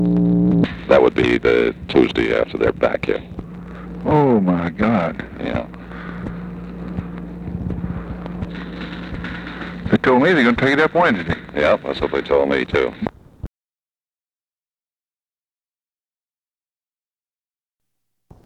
Conversation with LARRY O'BRIEN, July 7, 1964
Secret White House Tapes